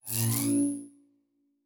pgs/Assets/Audio/Sci-Fi Sounds/Electric/Device 7 Start.wav at 7452e70b8c5ad2f7daae623e1a952eb18c9caab4